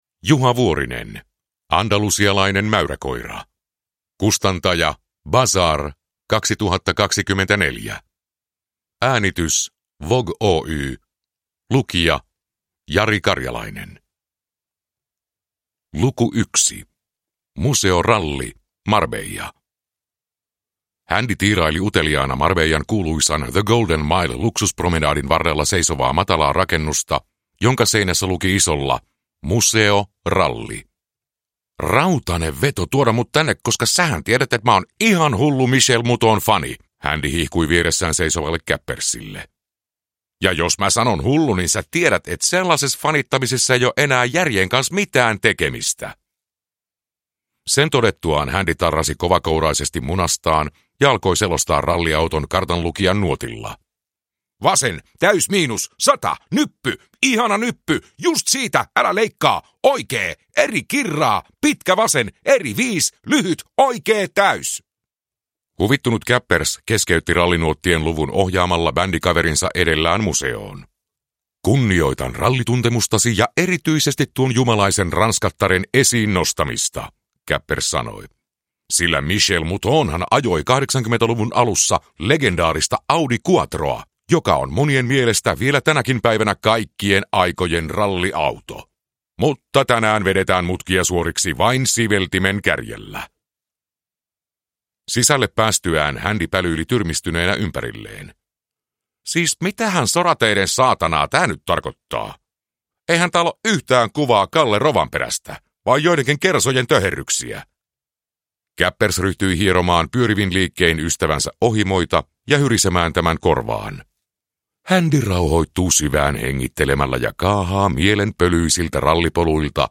Andalusialainen mäyräkoira – Ljudbok